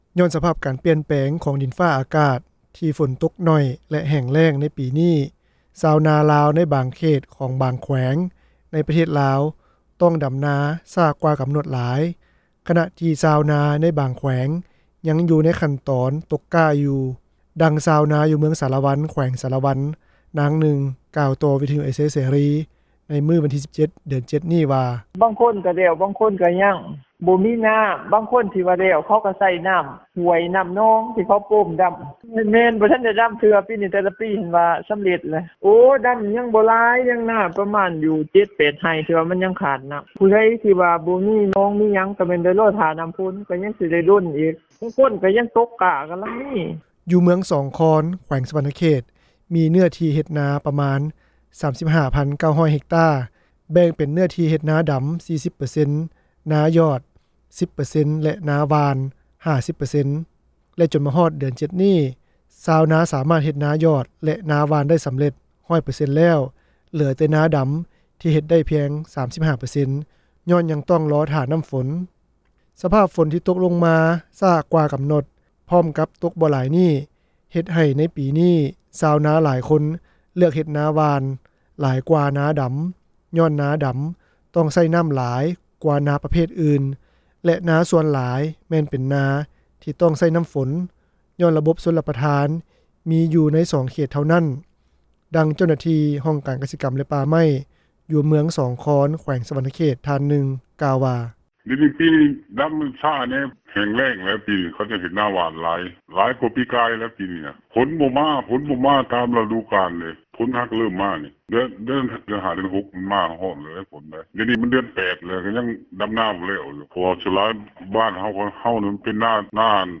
ດັ່ງຊາວນາ ຢູ່ເມືອງສາລະວັນ ແຂວງສາລະວັນ ນາງໜຶ່ງ ກ່າວຕໍ່ວິທຍຸເອເຊັຽເສຣີ ໃນມື້ວັນທີ 17 ເດືອນ 7 ນີ້ວ່າ:
ດັ່ງຊາວນາ ຢູ່ເມືອງໄຊທານີ ນະຄອນຫຼວງວຽງຈັນ ທ່ານໜຶ່ງ ກ່າວວ່າ: